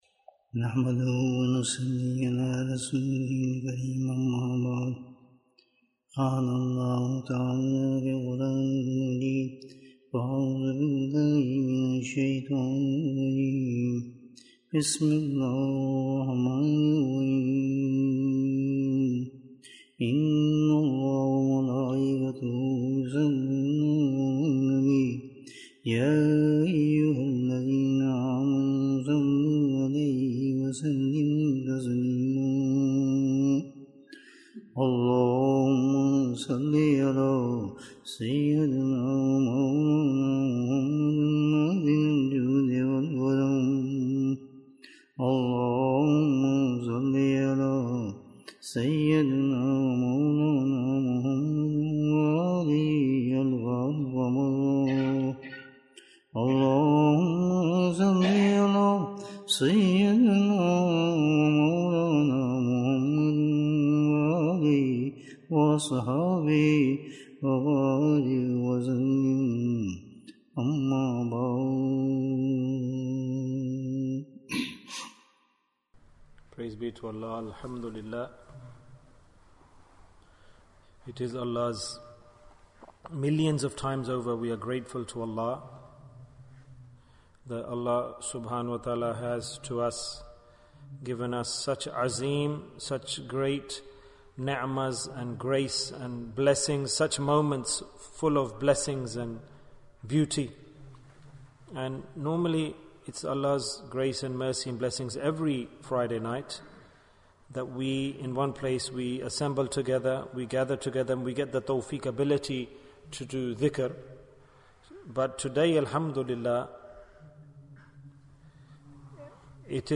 Dhikr in the Night of Arafah Bayan